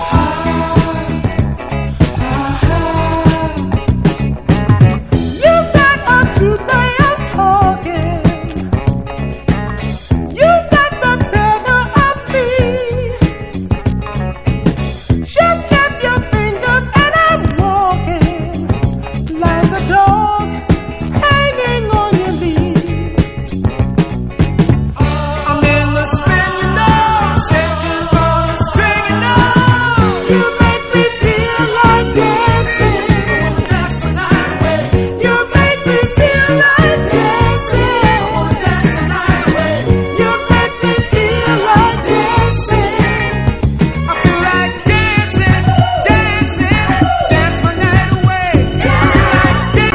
WORLD / OTHER / FRENCH / 60'S BEAT / GIRL POP / FUNK
選りすぐりのフレンチ・グルーヴをコンパイル！